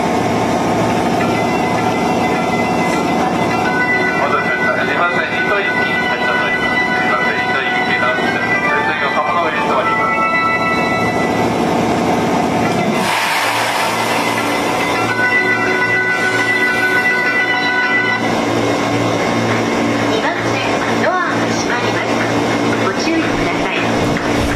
発車メロディーは東海道線標準のものです。